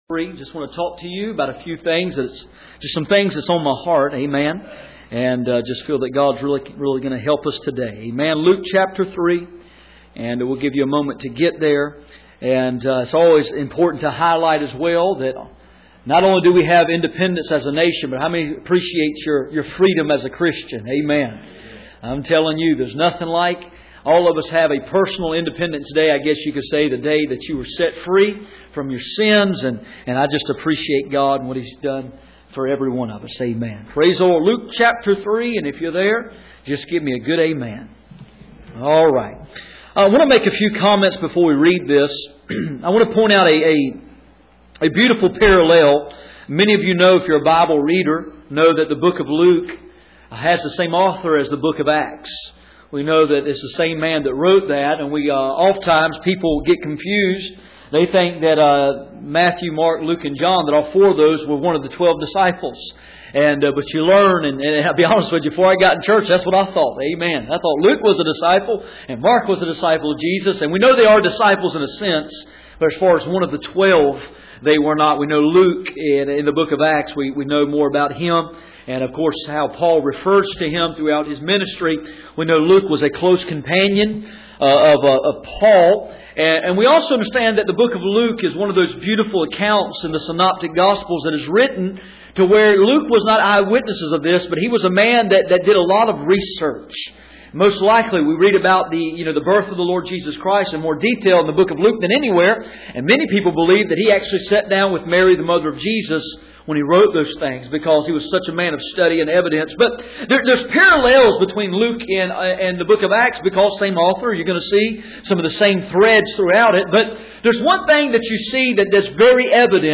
None Passage: Luke 3:21-22 Service Type: Sunday Morning %todo_render% « Word of Knowledge The proofs of HIS presence